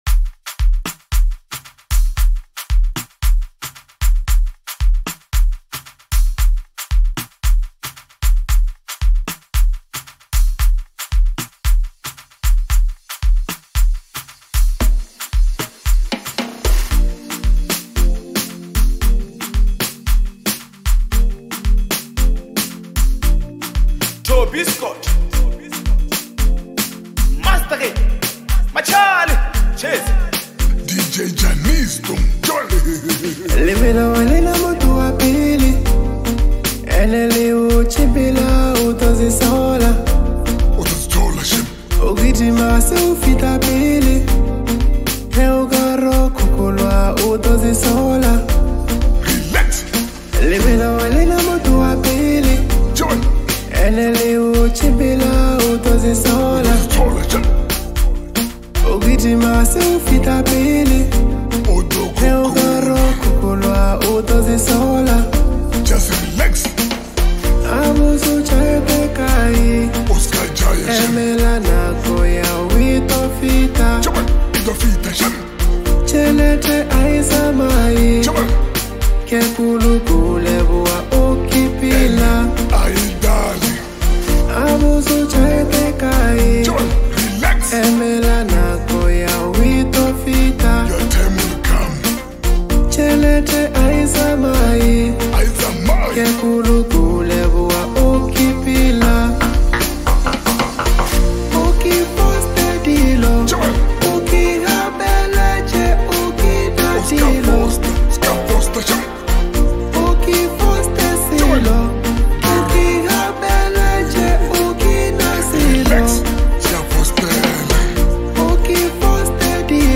soulful and rhythmic Afro house anthem